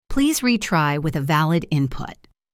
wrong-input.mp3